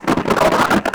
MONSTERS_CREATURES
ALIEN_Communication_27_mono.wav